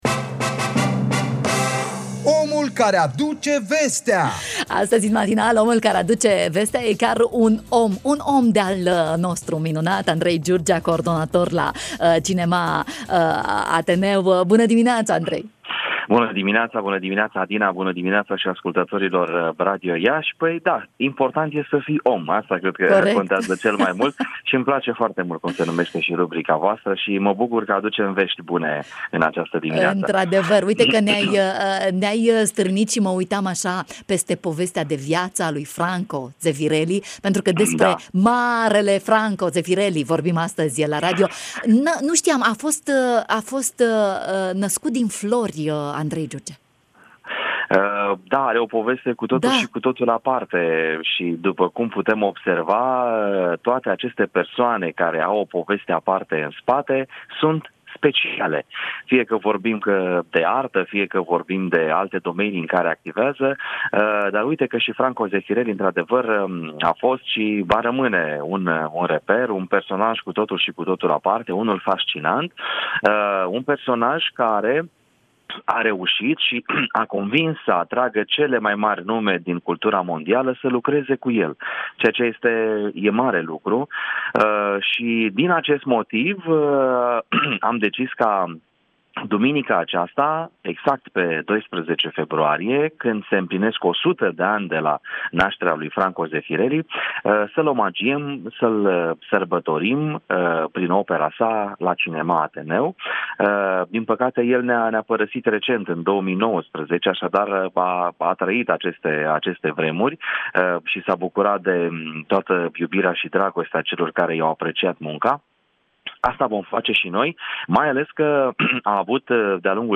în direct, în matinalul Radio România Iași